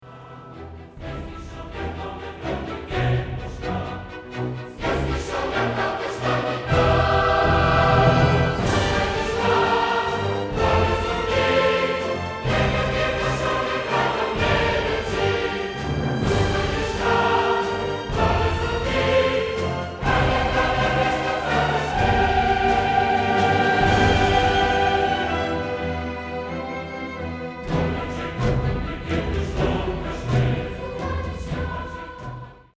Les augures avaient été consultées pour une météo parfaite et l'absence de concurrence des gladiateurs du ballon rond ;-)
Le public se souviendra longtemps de ces enfants colorés au coeur du chœur, chantant à pleins poumons "
l'orchestre et sa majestueuse section de cuivres, les chœurs et leur harmonieuse cohésion et en première mondiale des danseurs bigarrés en apesanteur